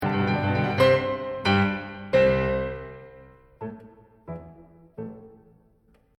And then he continues to use it, and use it, those three notes that just seemed to be a simple, very simple end.